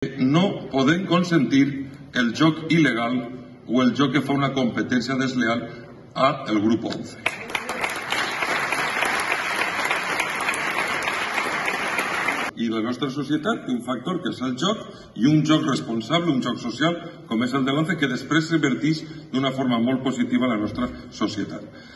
expresó durante un pasaje pronunciado en valenciano formato MP3 audio(0,84 MB) de su discurso- “merece su decidida defensa, como juego responsable frente a la creciente extensión del juego ilegal en nuestro país.